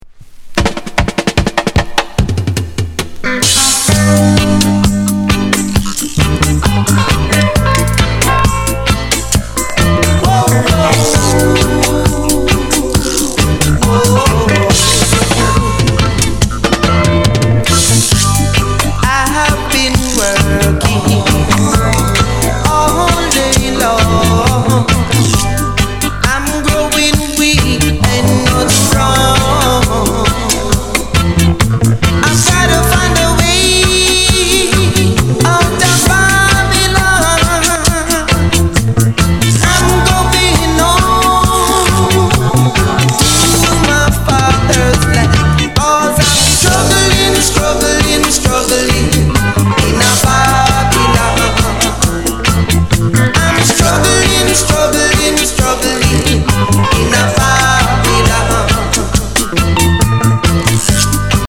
Genre: Reggae Format